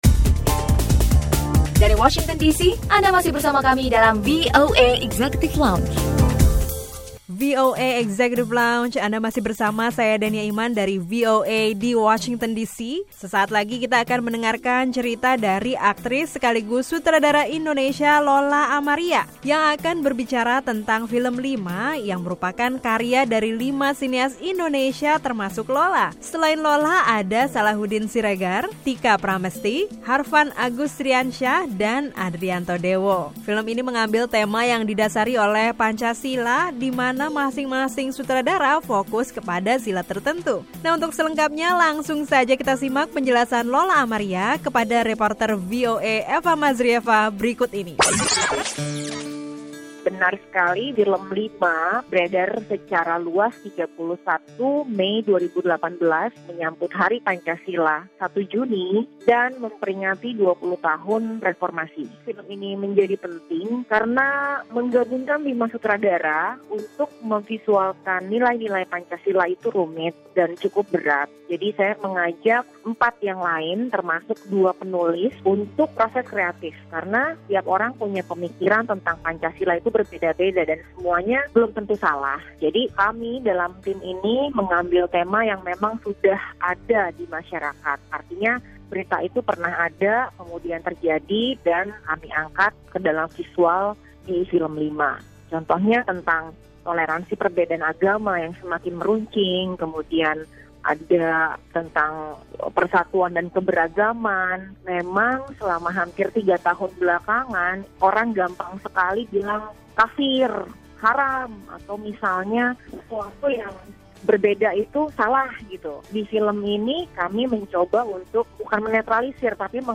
Simak cerita sutradara Indonesia, Lola Amaria seputar film "LIMA" yang merupakan kolaborasi dengan empat sutradara lainnya.